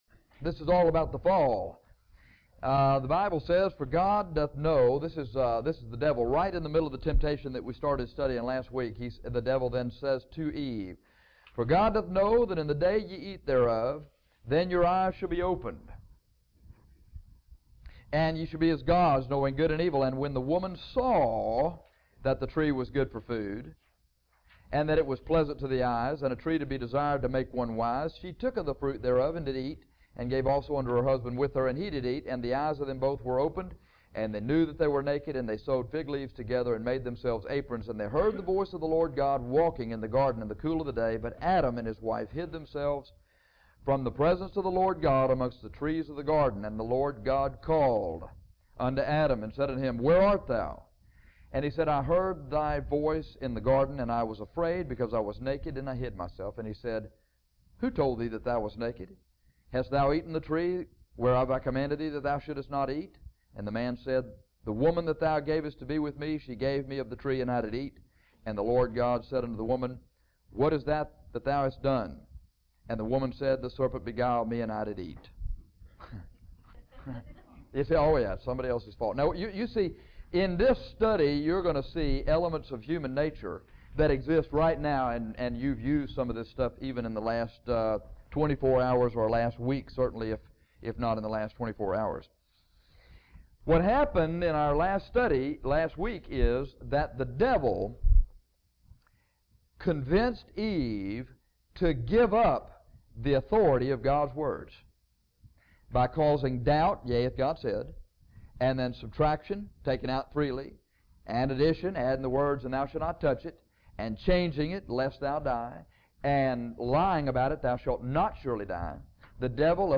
This lesson studies the fall of Man in the garden of Eden.